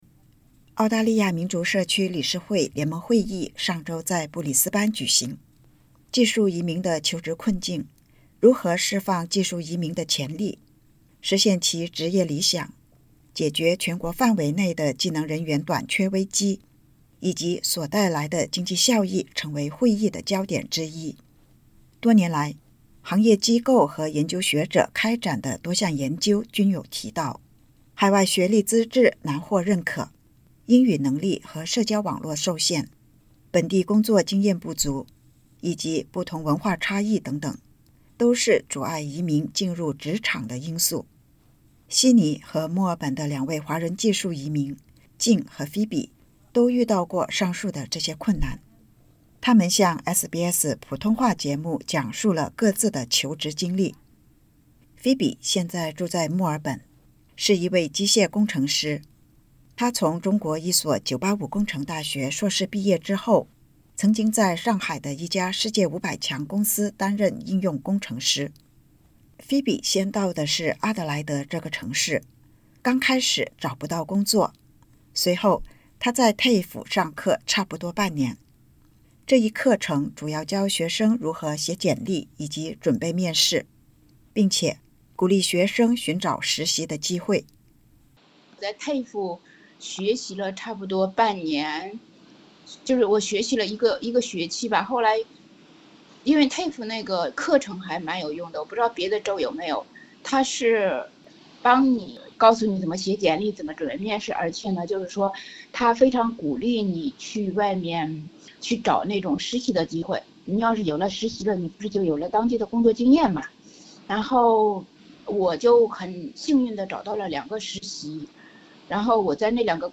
悉尼和墨尔本的两位华人技术移民向SBS普通话节目讲述各自的求职经历：从针对职位需求修改简历到面试，从一次次面试到面对失望、自我怀疑，再到调整心态，继续努力、提升专业技能，终于找到自己心仪工作。